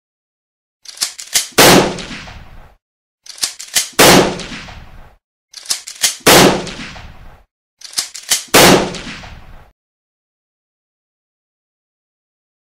جلوه های صوتی
دانلود صدای شلیک تفنگ شاتگان بلند از ساعد نیوز با لینک مستقیم و کیفیت بالا